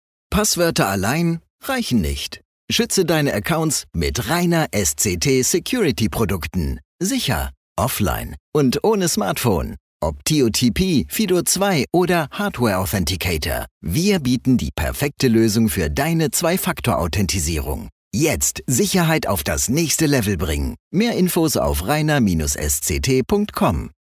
Male
Spanish (Latin American)
Radio Imaging